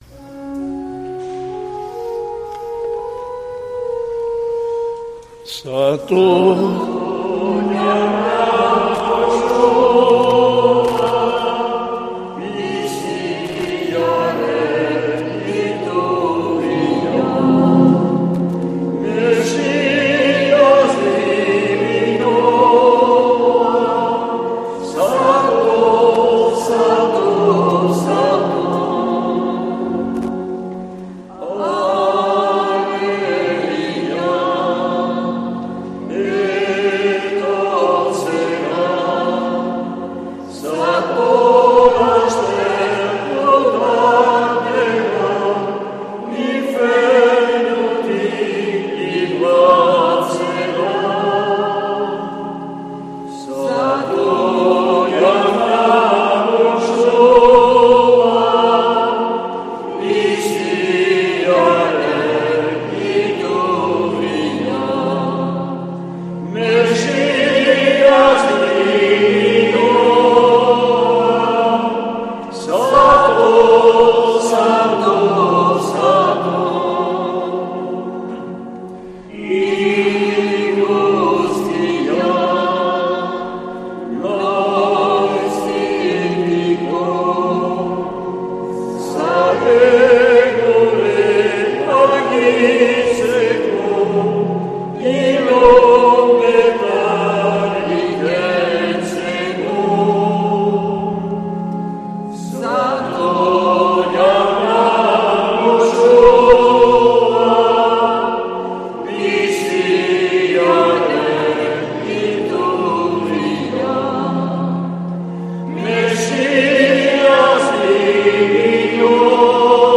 Accueil \ Emissions \ Vie de l’Eglise \ Célébrer \ Igandetako Mezak Euskal irratietan \ 2025-11-30 Abenduko 1.